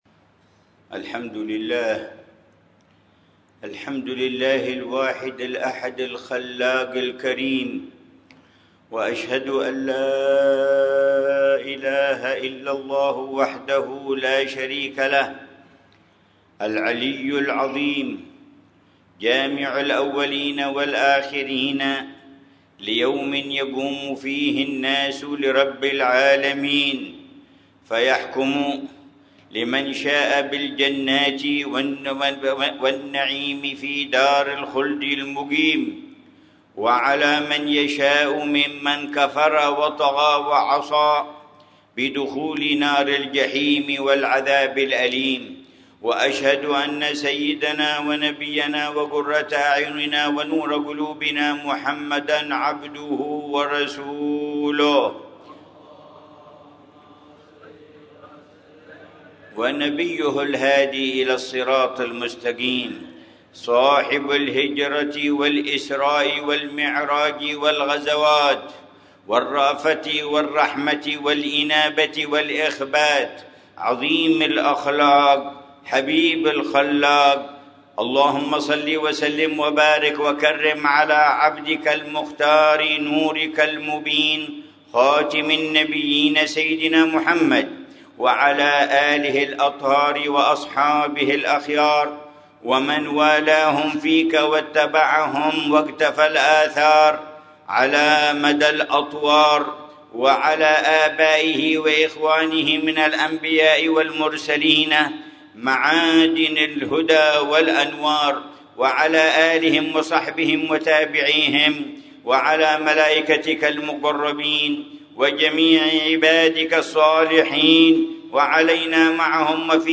خطبة الجمعة للعلامة الحبيب عمر بن محمد بن حفيظ في جامع النور، في حارة القرن، بمدينة سيئون، 16 محرم 1447هـ بعنوان: